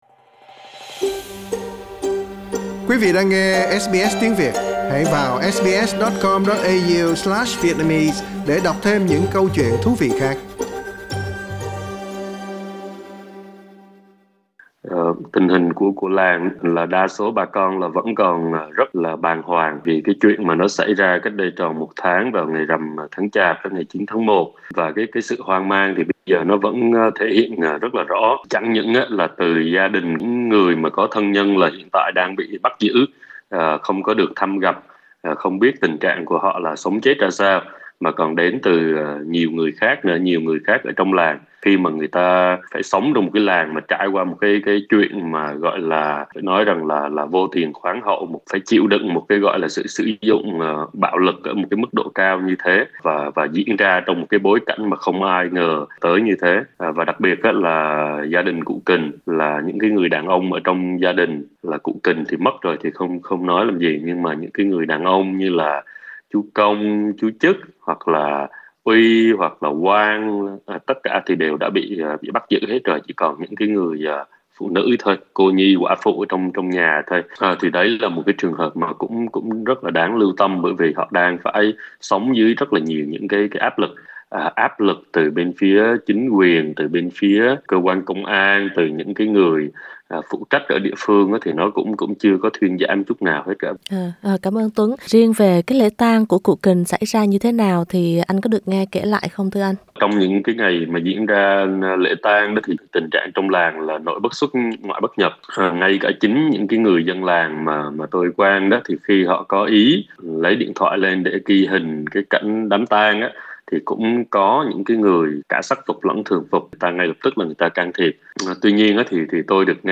nhà hoạt động